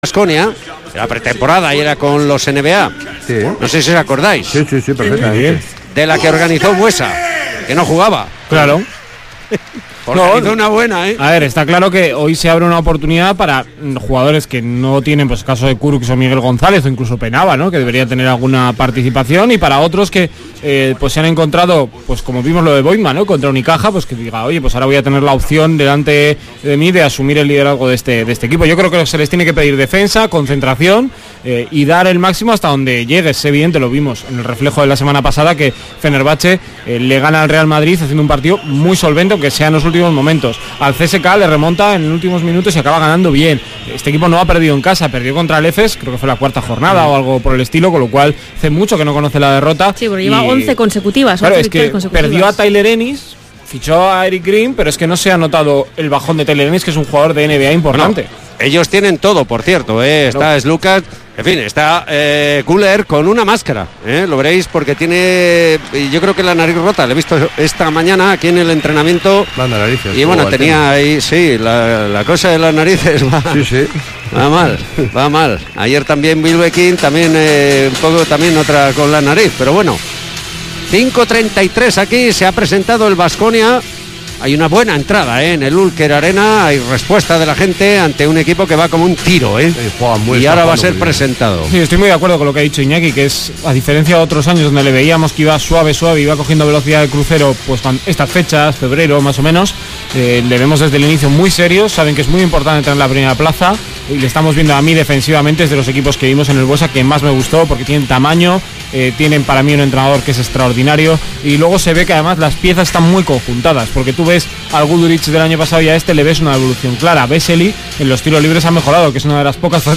Fenerbahce-Baskonia jornada 16 euroleague 2018-19 retransmisión completa Radio Vitoria